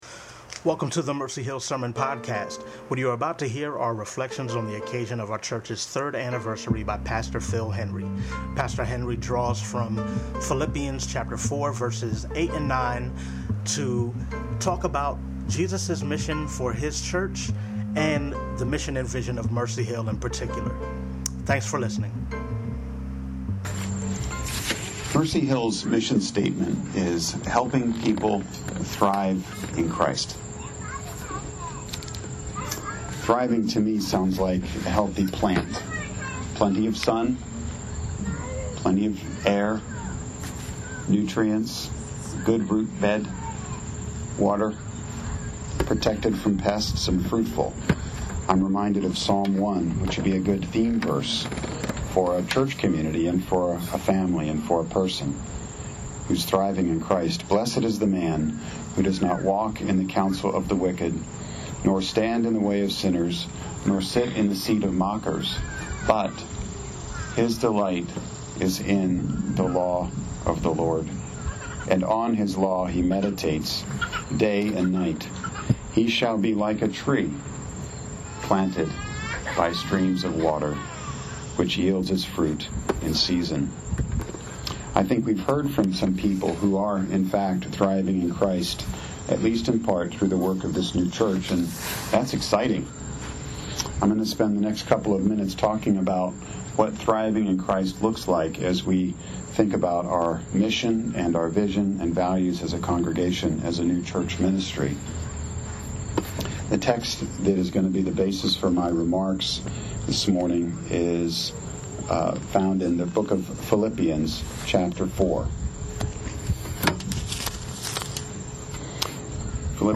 Mercy Hill Presbyterian Sermons - Mercy Hill NJ